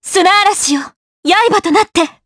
Laudia-Vox_Skill1_jp.wav